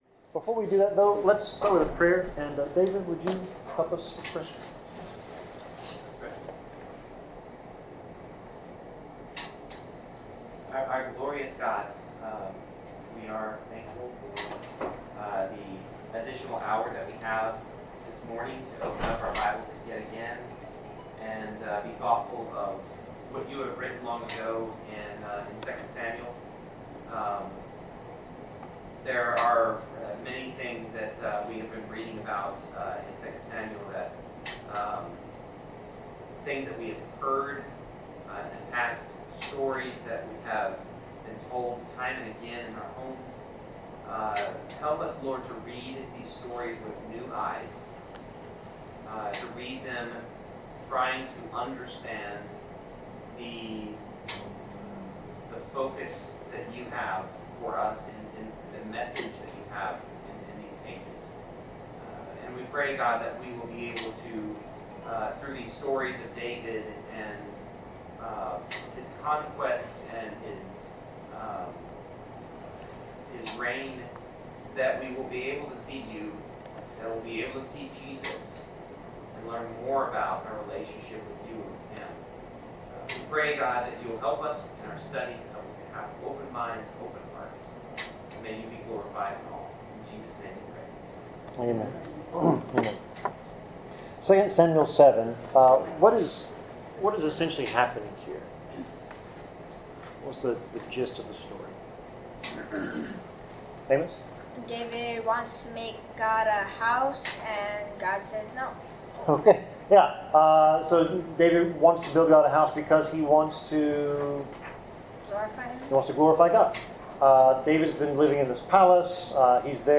Bible class: 2 Samuel 7-9 (The Greatness of David’s Kingdom)
Service Type: Bible Class